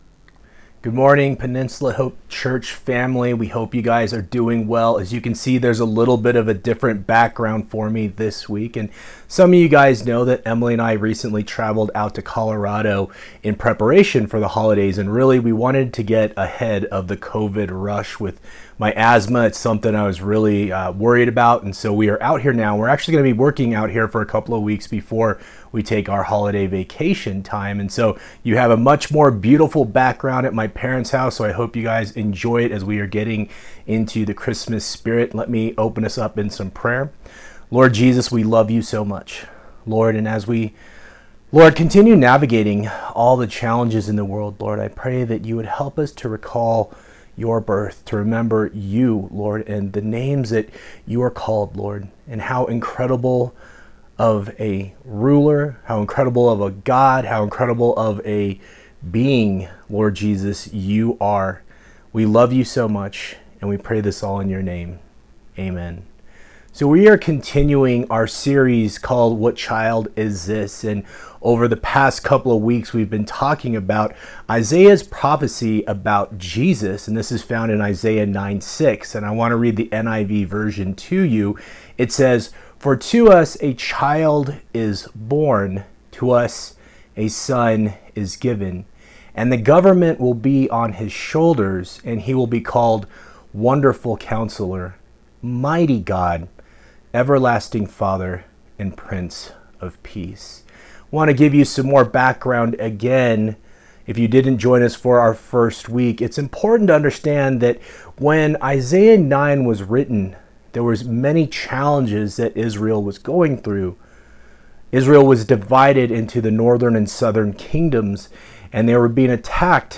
December 13, 2020 Sunday Message